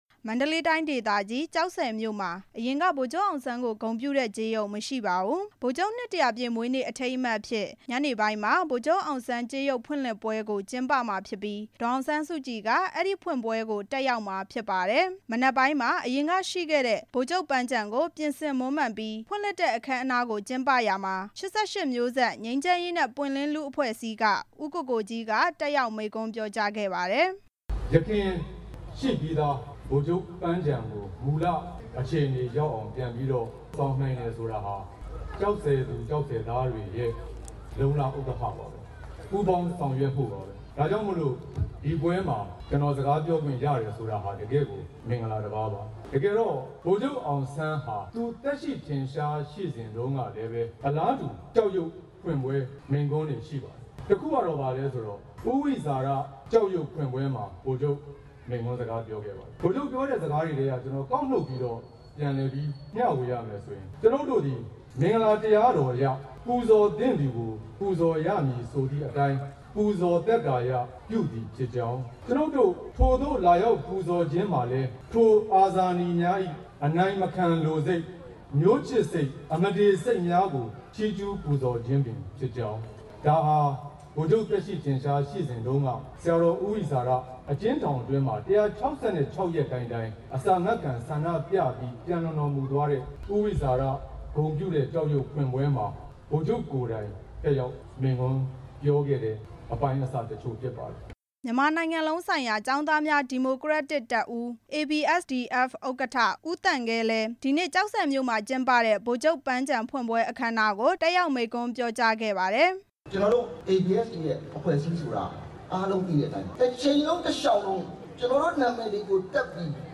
ဒီနေ့ မနက်က ပန်းခြံဖွင့်ပွဲအခမ်းအနားကို တက်ရောက်ခဲ့တဲ့ ၈၈ မျိုးဆက် ငြိမ်းချမ်းရေးနဲ့ ပွင့်လင်းလူ့အဖဲ့ွအစည်း ခေါင်းဆောင်တစ်ဦးဖြစ်သူ ဦးကိုကိုကြီးက ဗိုလ်ချုပ်အောင်ဆန်းအမှတ်တရ စကားပြောကြားခဲ့ပါတယ်။